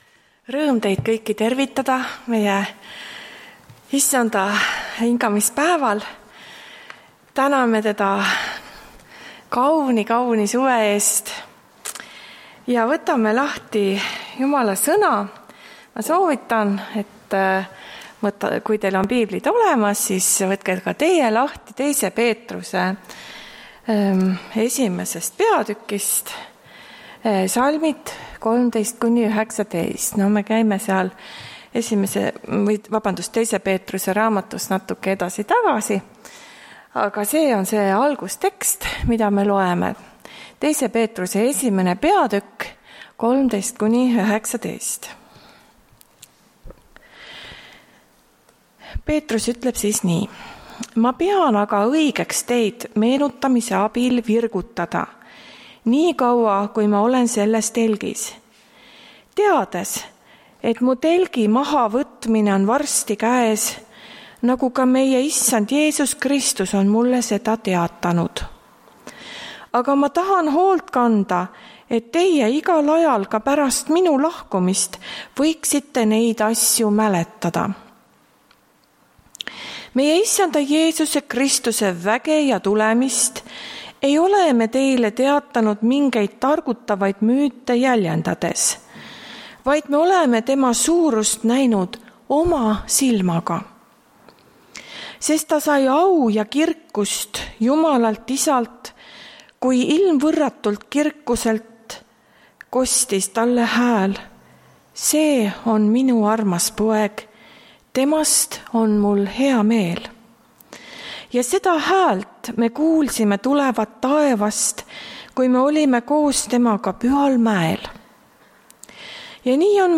Tartu adventkoguduse 10.07.2021 hommikuse teenistuse jutluse helisalvestis.